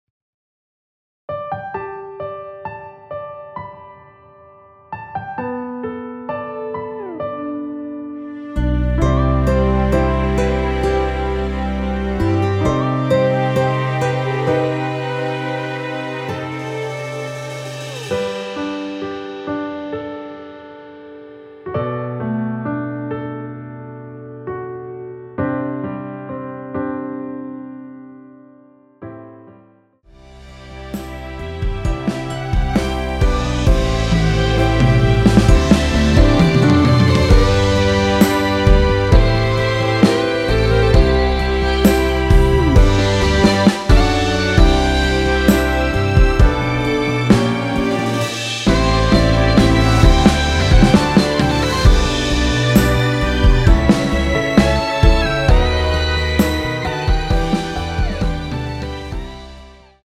원키에서(+4)올린 MR입니다.
앞부분30초, 뒷부분30초씩 편집해서 올려 드리고 있습니다.